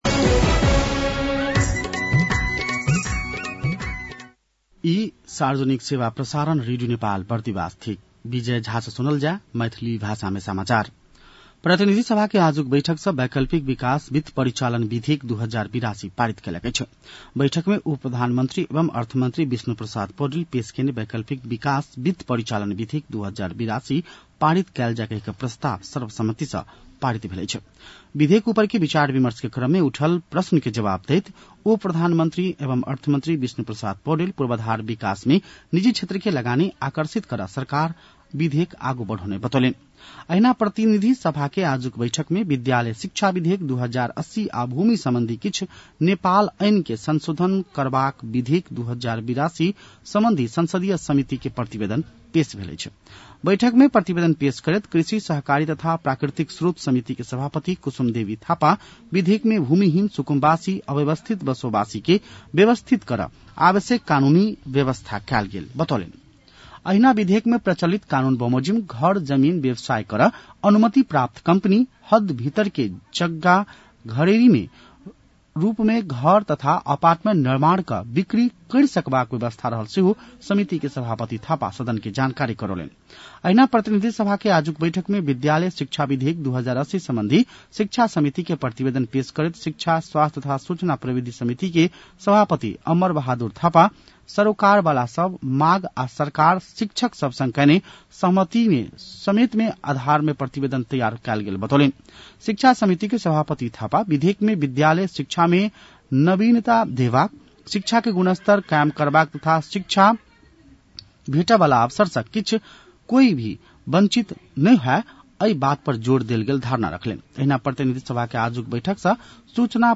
मैथिली भाषामा समाचार : ६ भदौ , २०८२